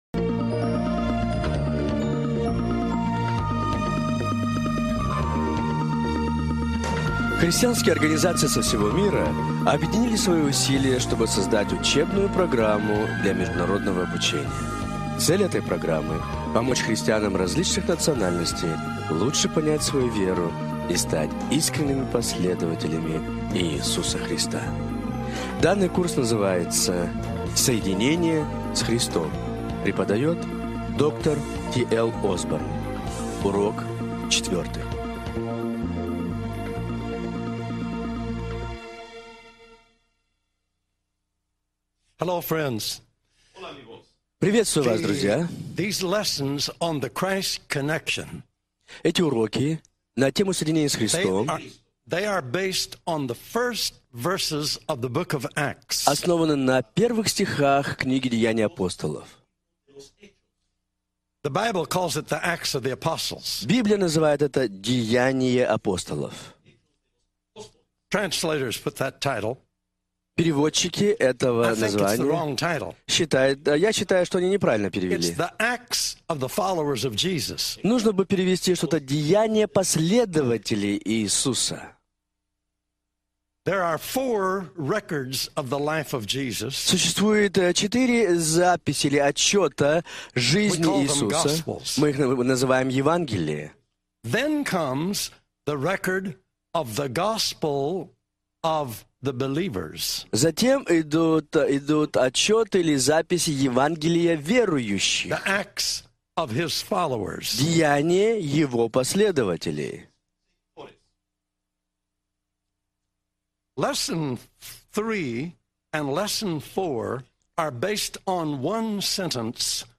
Наша реакция Браузер не поддерживается supports HTML5 Слушать/скачать mp3 – часть 1 Урок 4. Наши действия Браузер не поддерживается supports HTML5 Слушать/скачать mp3 – часть 1 Урок 5.